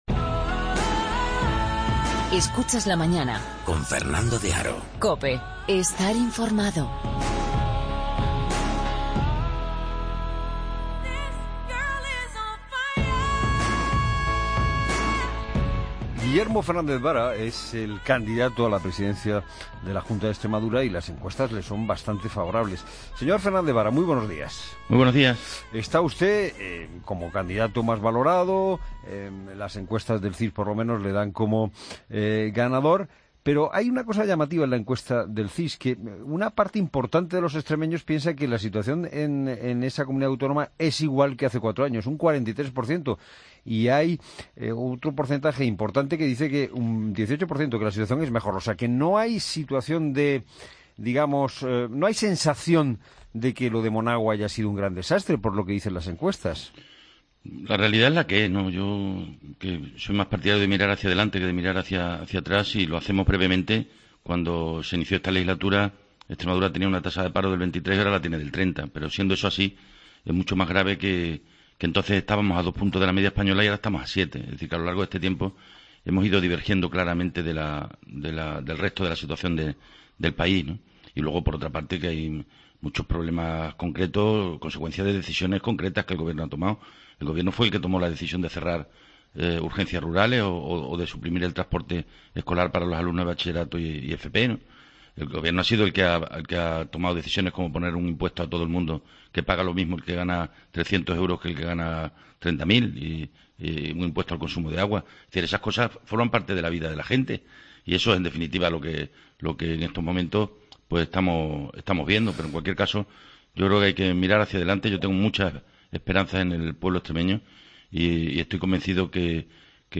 AUDIO: Escucha la entrevista al socialista en "La Mañana Fin de Semana".